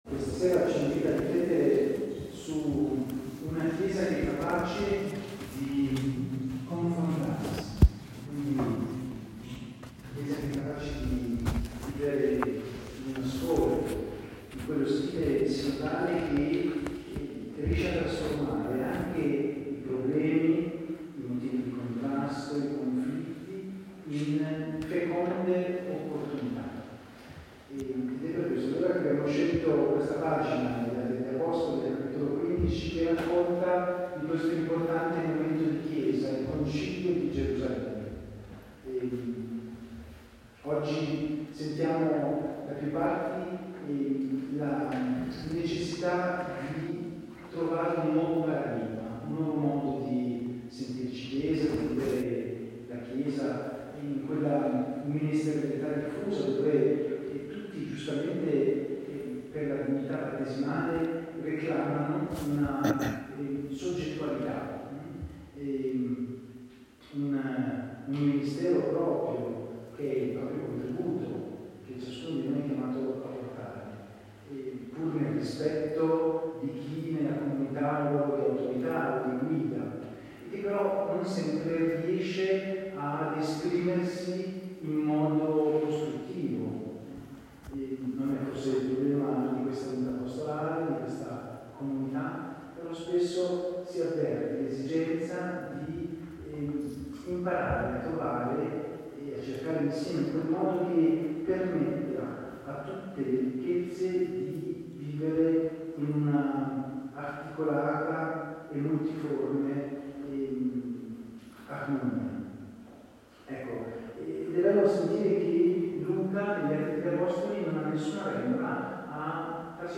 La presenza dei seminaristi ha contribuito a dare bellezza alla celebrazione del 13 del mese che si è tenuta a Vedriano il 13 settembre.